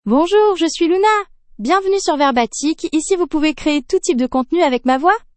LunaFemale French AI voice
Luna is a female AI voice for French (France).
Voice sample
Listen to Luna's female French voice.
Luna delivers clear pronunciation with authentic France French intonation, making your content sound professionally produced.